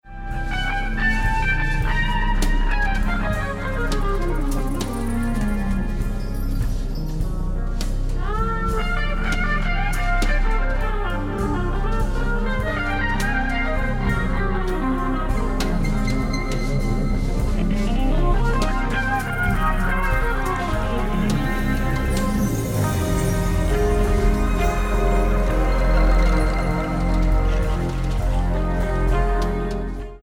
guitar: Agostin Z24